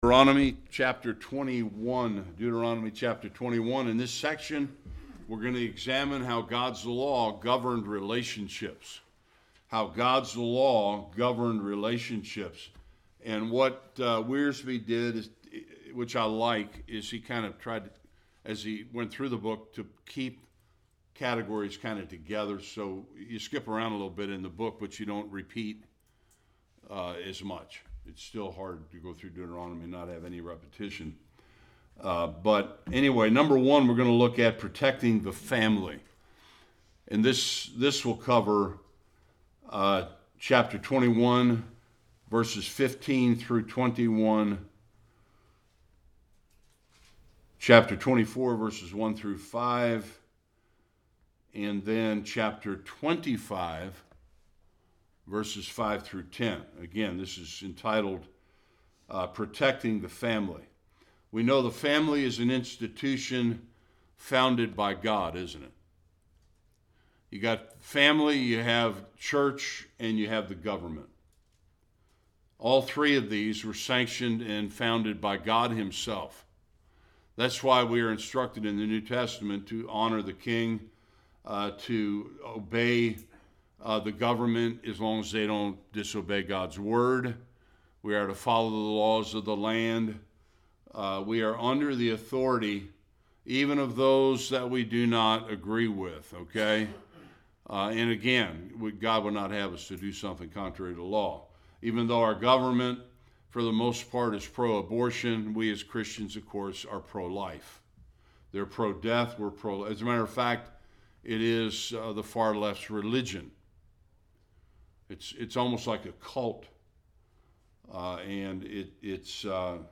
15-21 Service Type: Sunday School God’s Law and the governing of relationships in the Promised land.